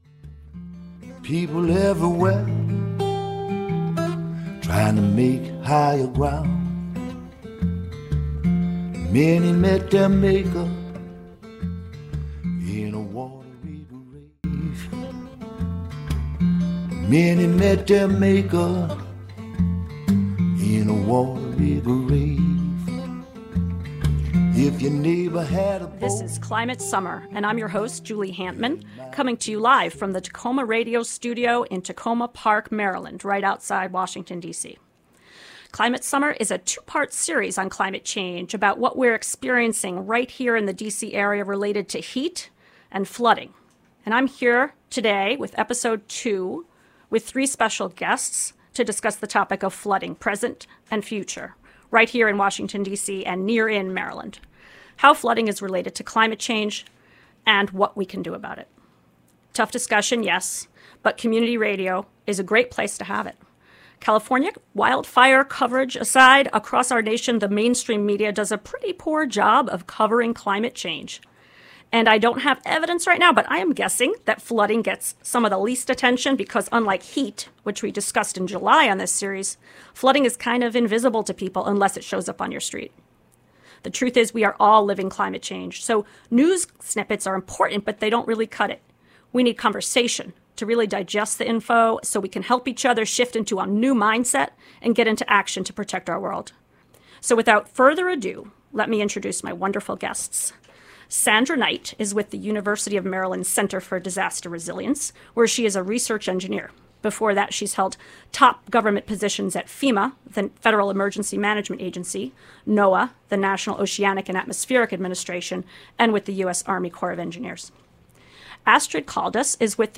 Join us for an hour-long discussion that conveys information, insight, and hope.
An expert roundtable conveys information, insight, and hope.